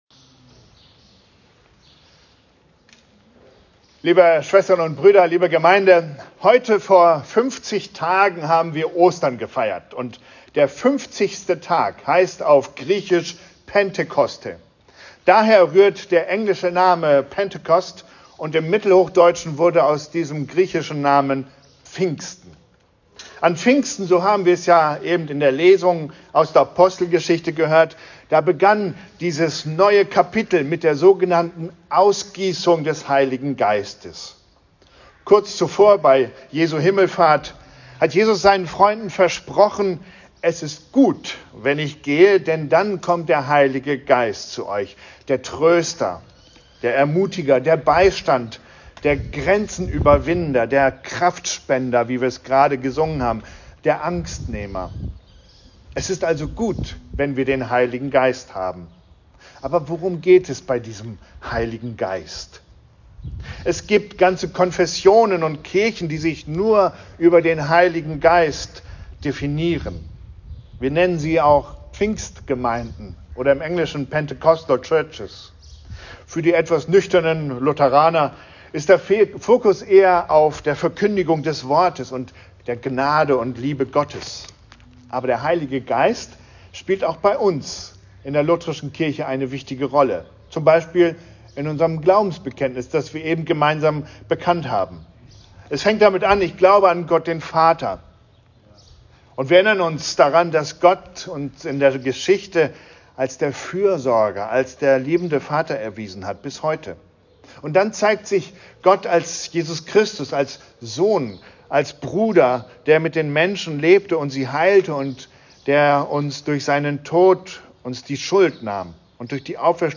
An Pfingstsonntag fand der Gottesdienst vor der St. Marien-Kirche statt.